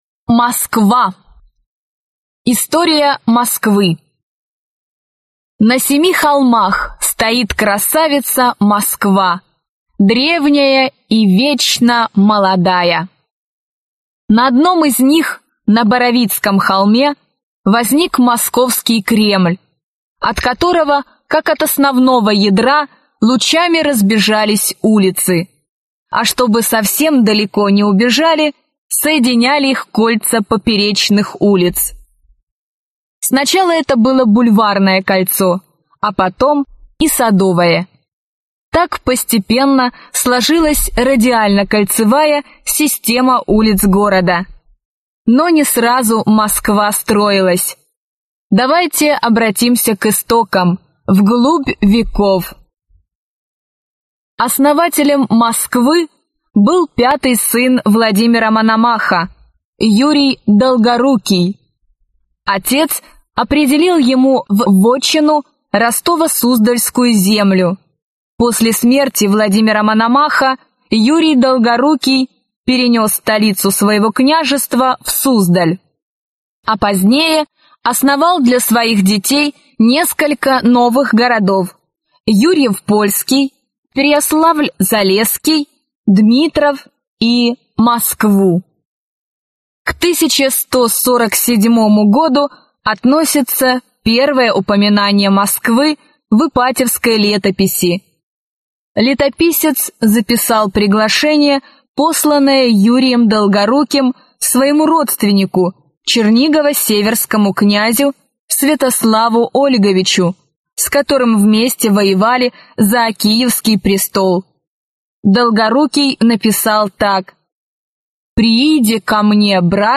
Аудиокнига Путеводитель по Москве. Кремль, Красная Площадь, исторический центр | Библиотека аудиокниг